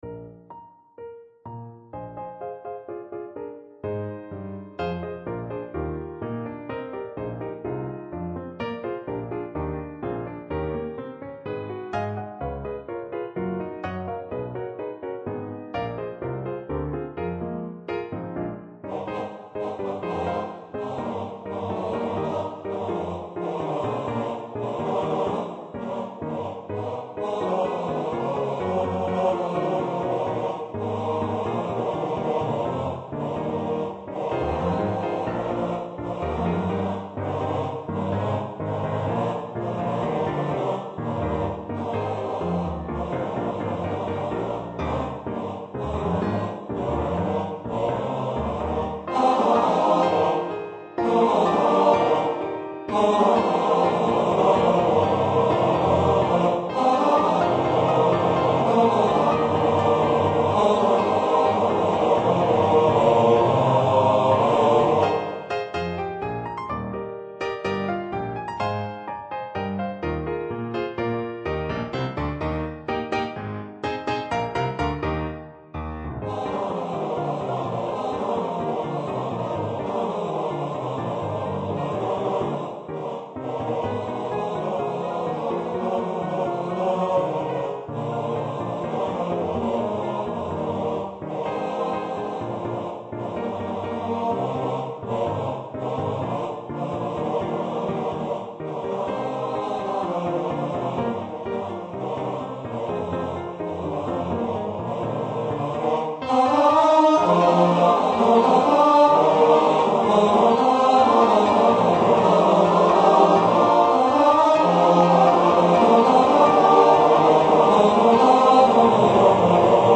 for male voice choir